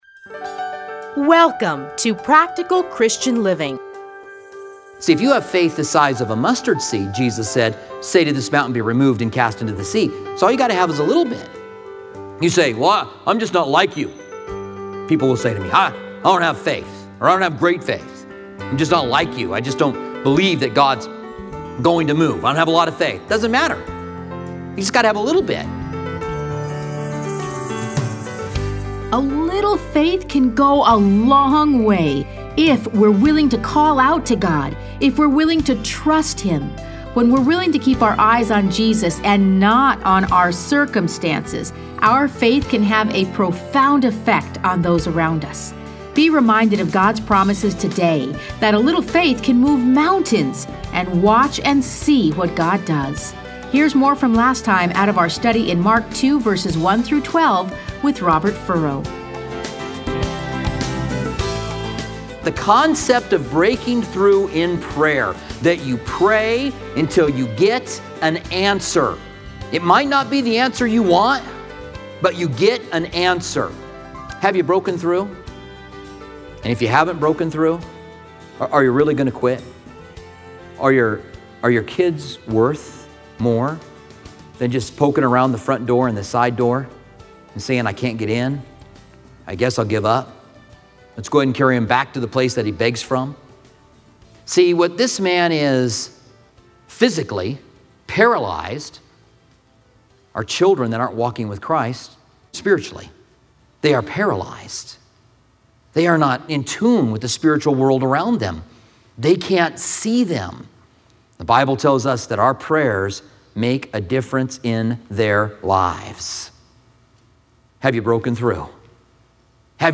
Listen to a teaching from Mark 2:1-12.